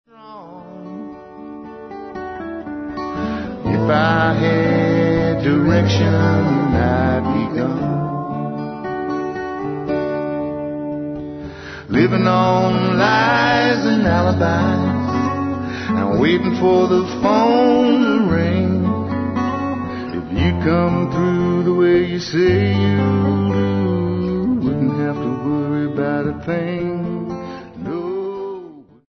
lead vocals, guitar, dulcimer
Recorded at General Store Recording